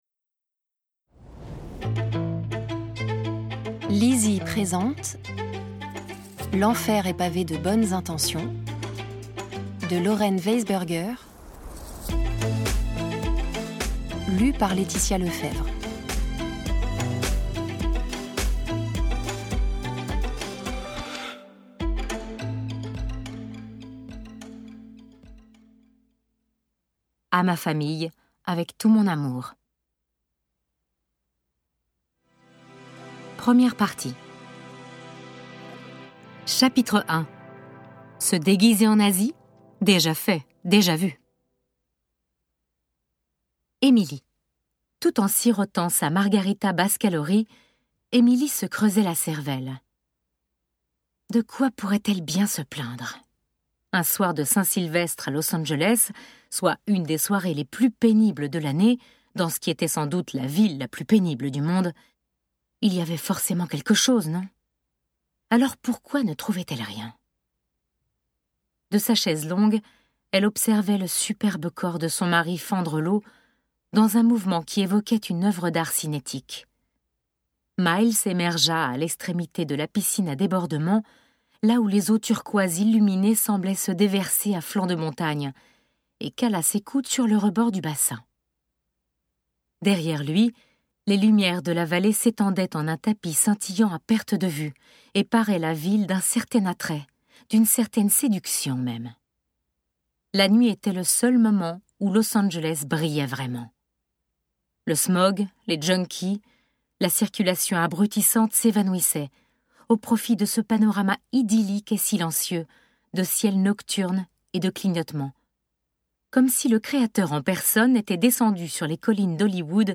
je découvre un extrait - L'Enfer est pavé de bonnes intentions de Lauren WEISBERGER